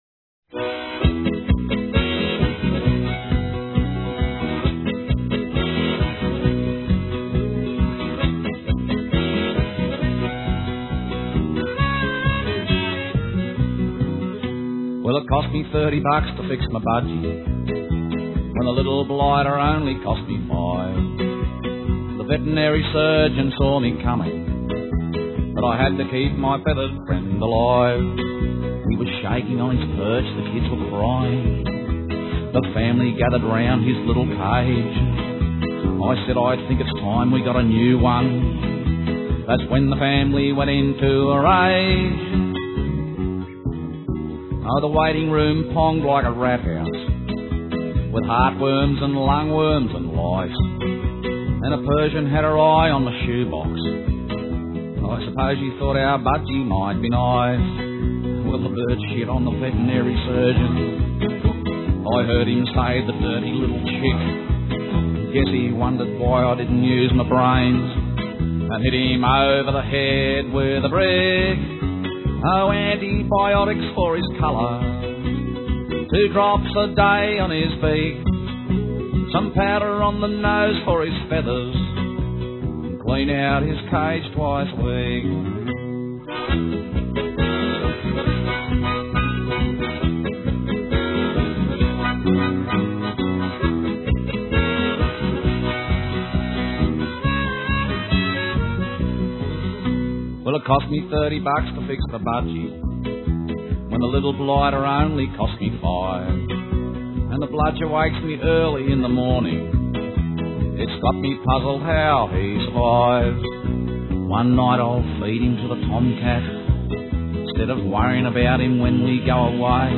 budgiesong.wav